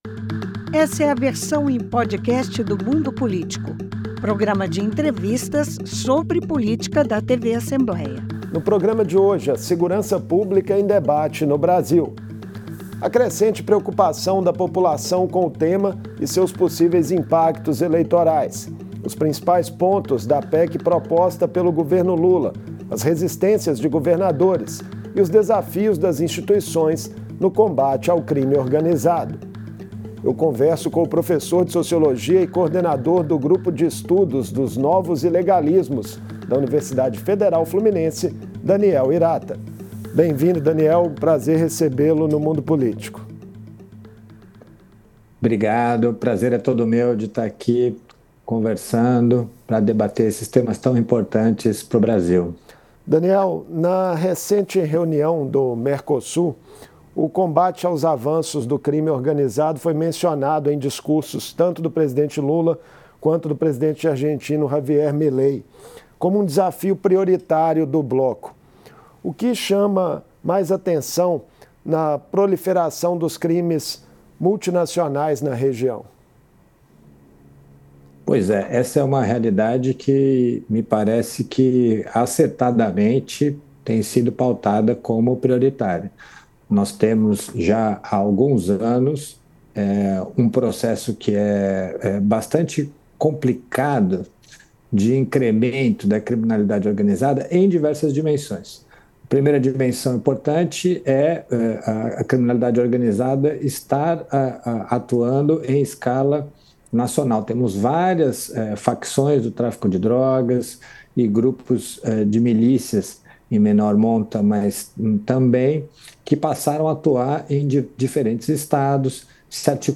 Debate: A segurança pública no Brasil